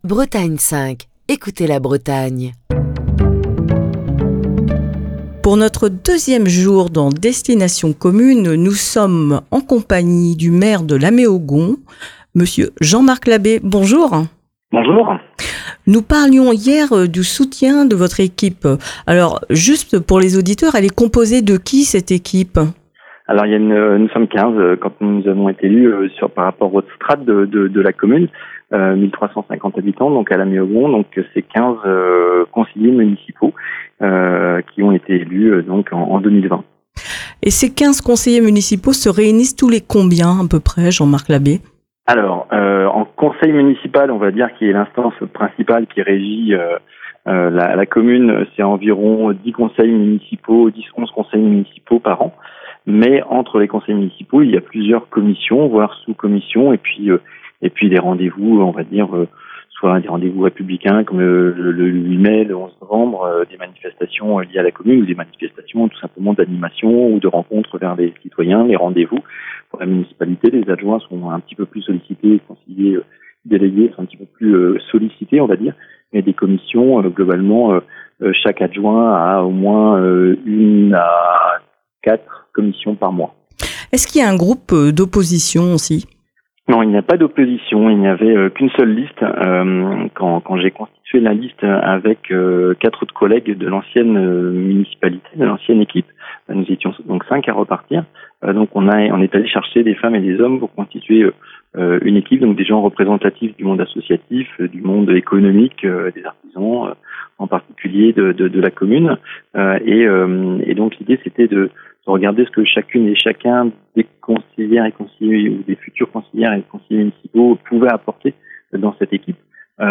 Jean-Marc Labbé, le maire de La Méaugon présente l'histoire de sa commune, les futurs projets et son riche tissu associatif